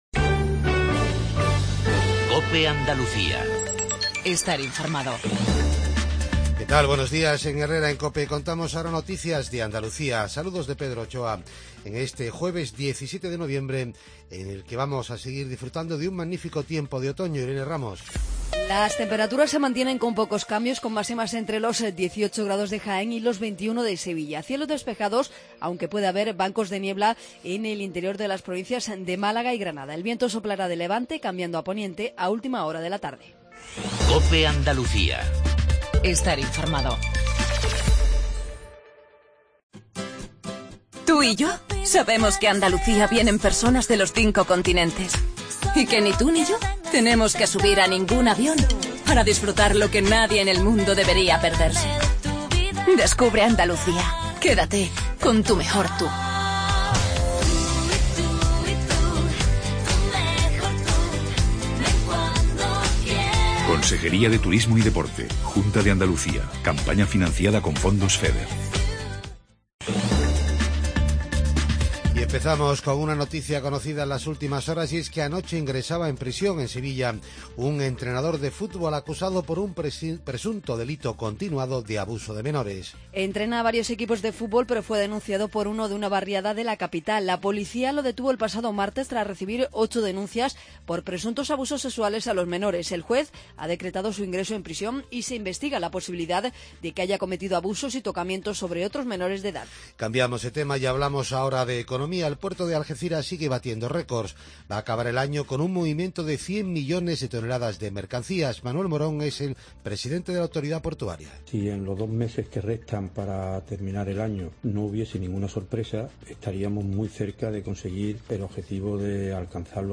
INFORMATIVO REGIONAL/LOCAL MATINAL 7:20